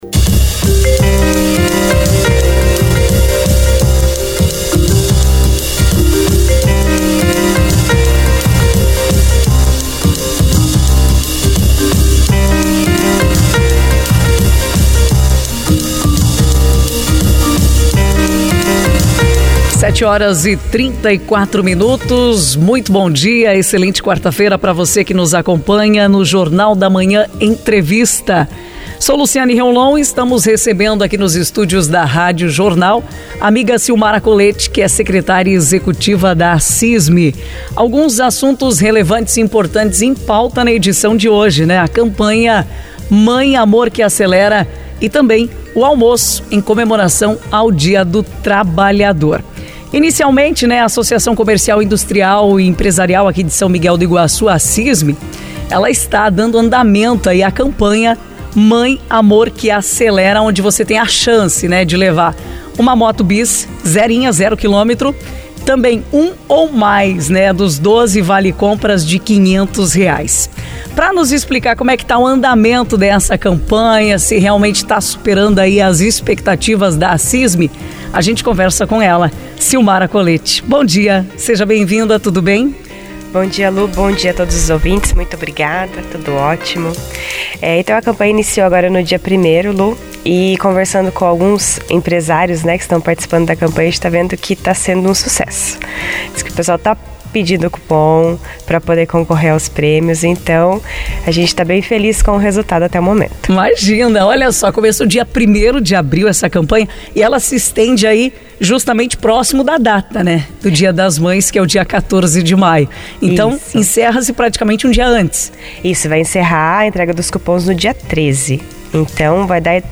JORNAL-DA-MANHA-ENTREVISTA-ACISMI.mp3